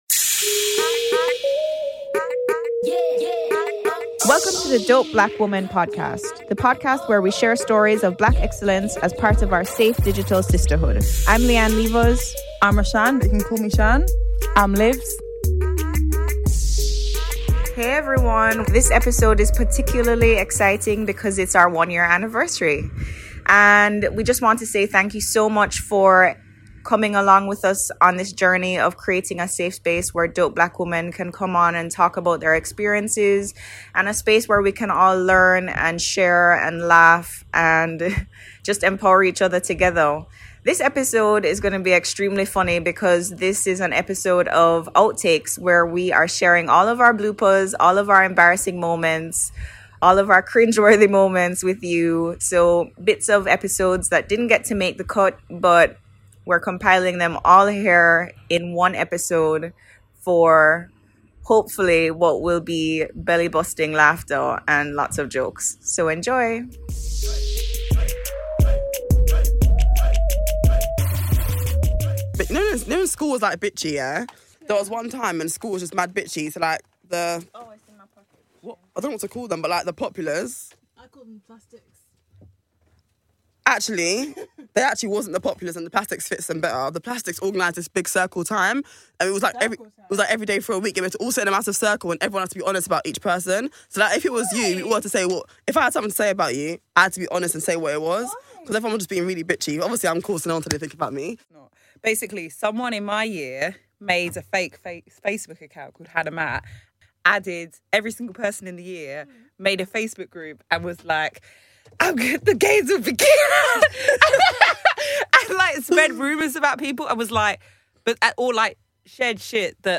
To celebrate one whole year of the Dope Black Women Podcast we're giving you some of the behind the scenes moments, the bits that didn't make the main show - our outtakes from the last year. Sit back, relax and enjoy some of our funniest moments.